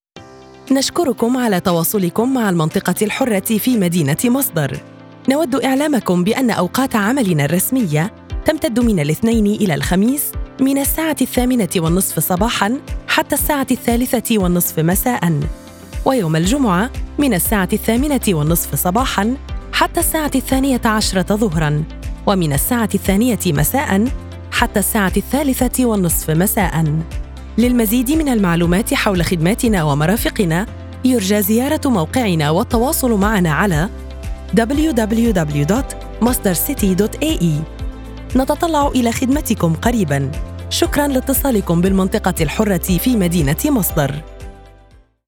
Interactive Telephony Response
Professional Arabic Voice-Over Service
Response Telephony Voice Female Ivr